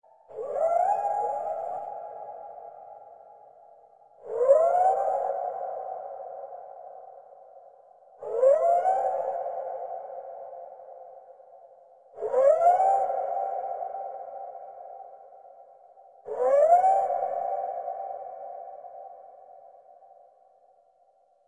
Tiếng Ma gọi trong Rừng vào Ban đêm